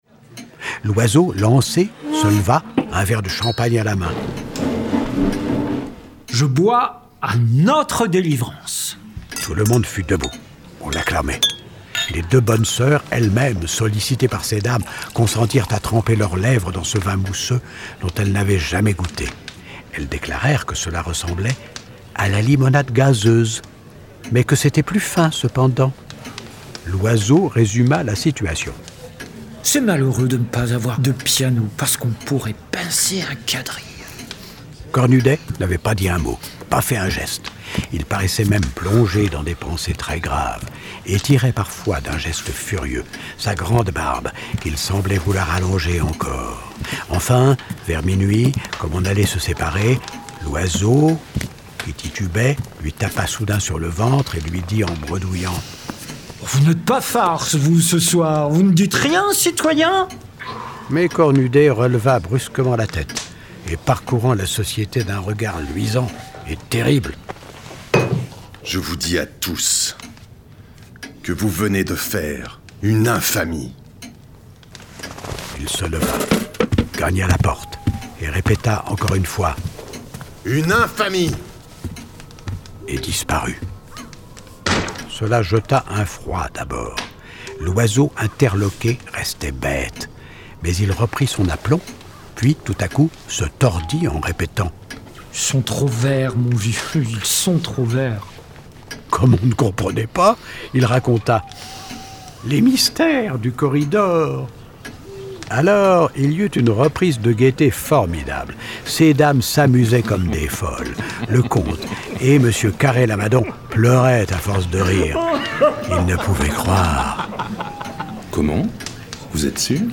2024-03-14 Parue en 1880, Boule de Suif a été depuis adaptée de nombreuses fois au théâtre, au cinéma, à la télévision. La mise en scène sonore de l’œuvre met en lumière toute l’ambiance si particulière du contexte historique de la guerre de 1870, et l’interprétation du narrateur et des dialogues amplifie la c...